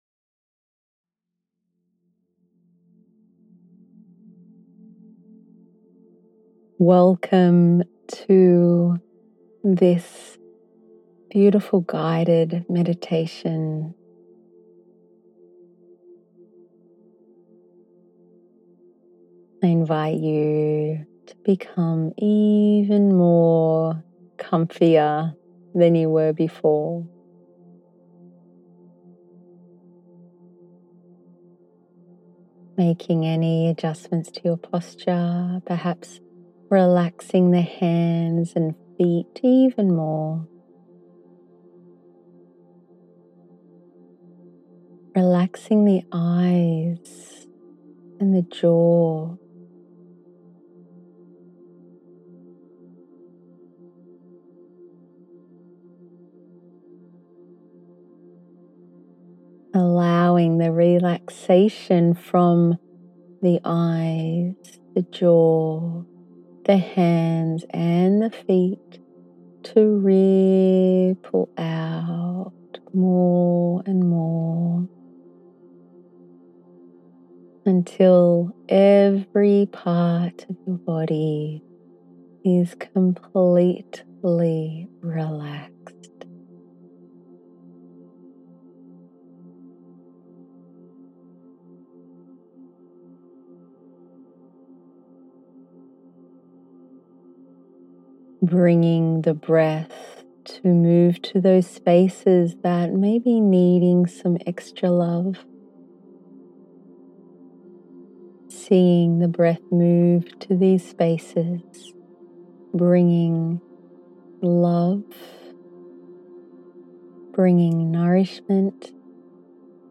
Production and music by my partner
‘Be Present’  3 minute Guided Meditation